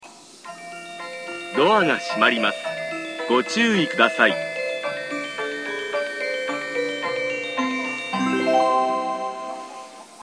○発車メロディー○
発車メロディー密着収録したので高音質に収録が出来ました。途中切り防止システムがついているため必ずフルコーラスが流れます。